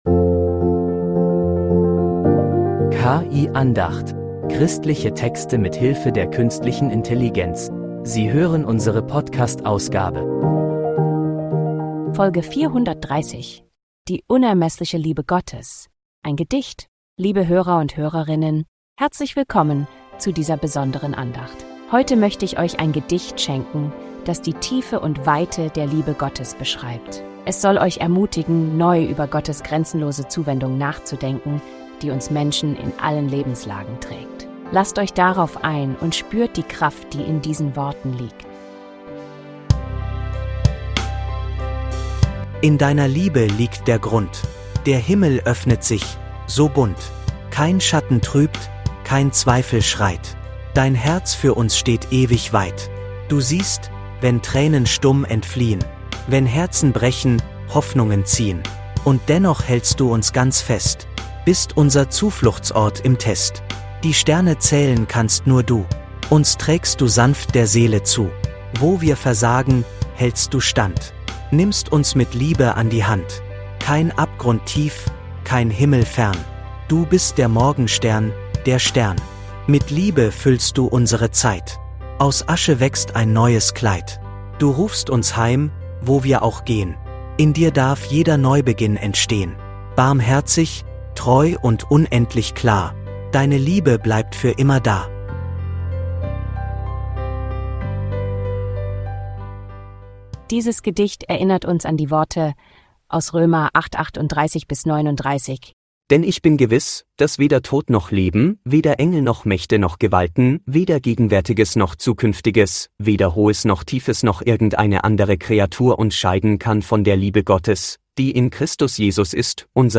Din Gedicht